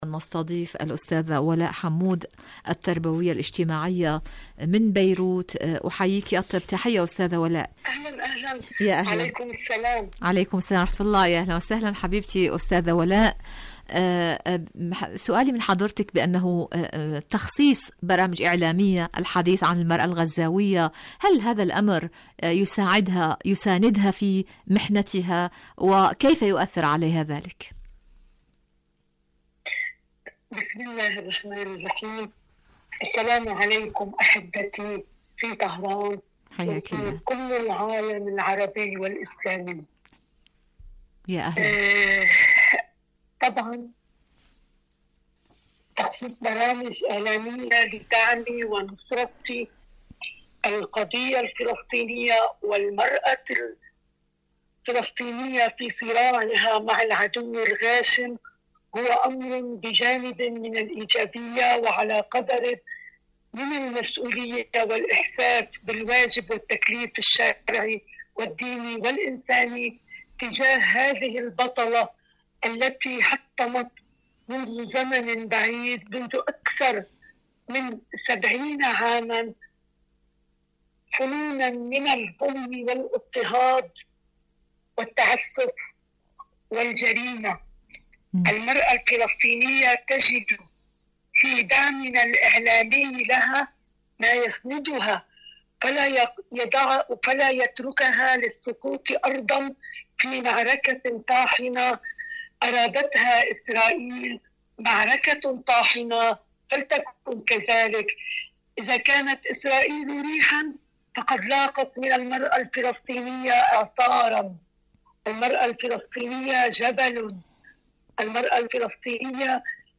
إذاعة طهران-عالم المرأة: مقابلة إذاعية